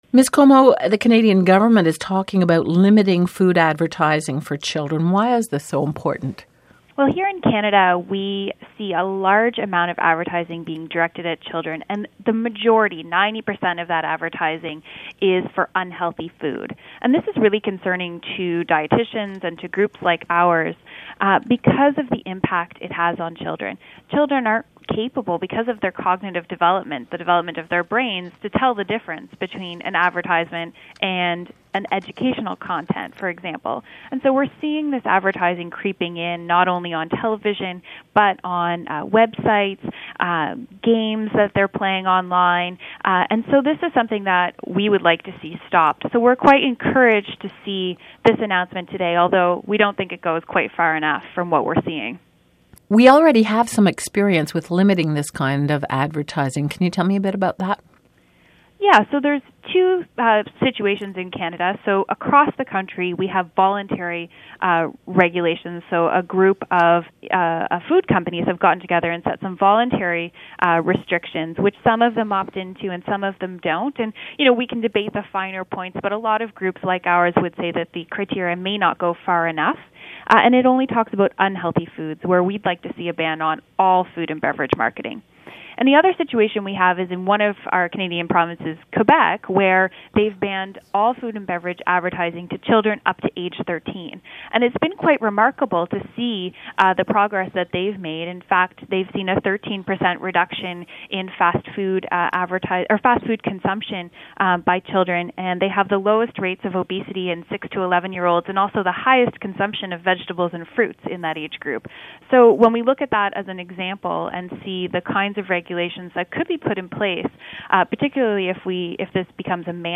food-interview.mp3